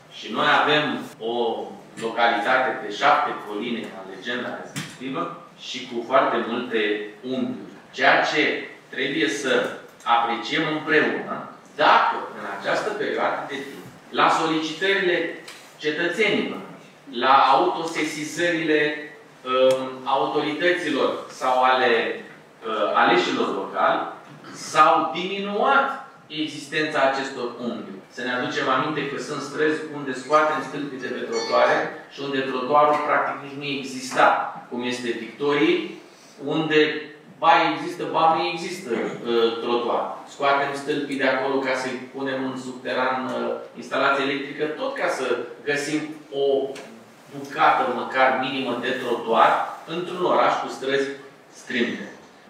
Azi, 27 februarie 2025, Consiliul Local Tulcea s-a reunit într-o nouă ședință ordinară pentru a discuta o serie de proiecte ce vizează, printre altele, administrarea domeniului public, cofinanțarea serviciilor sociale destinate persoanelor fără adăpost și măsuri fiscale pentru contribuabilii aflați în dificultate.
În ceea ce privește gestionarea parcărilor, edilul a recunoscut dificultățile legate de configurația orașului, dar a subliniat că Primăria face eforturi în acest sens: